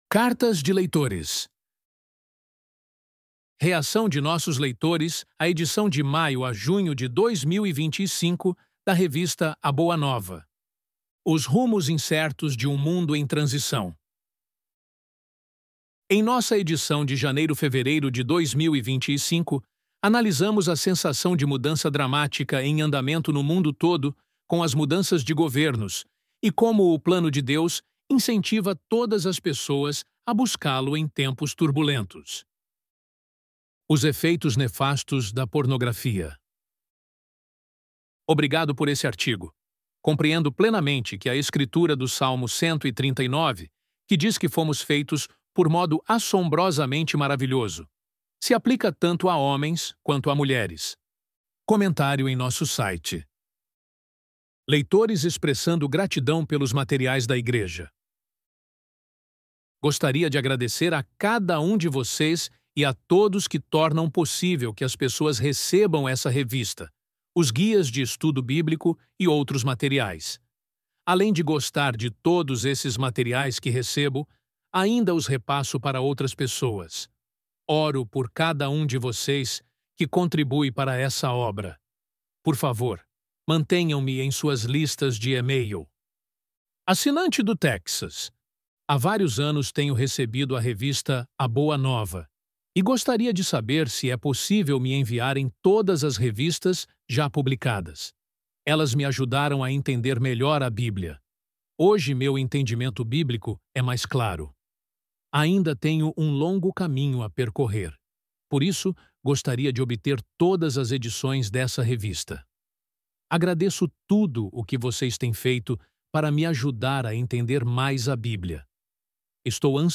Loading the Elevenlabs Text to Speech AudioNative Player...